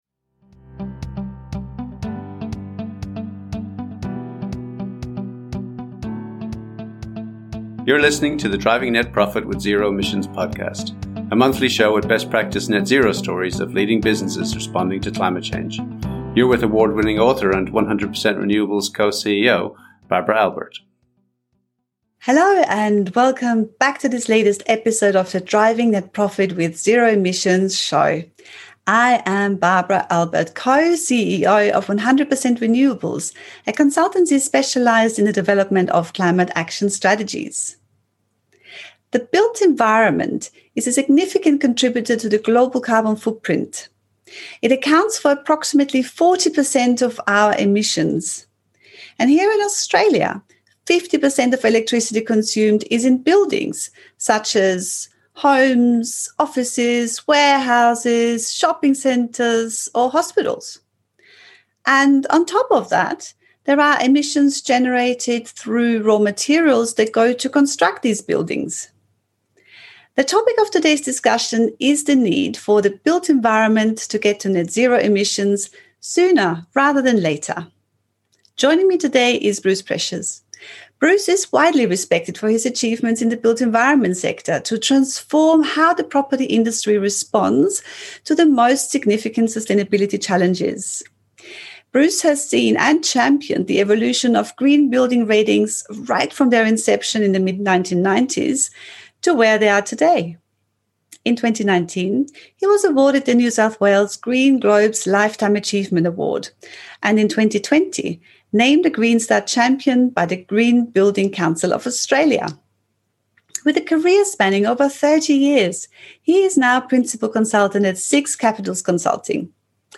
each episode showcases an interview with expert guests from leading climate action companies.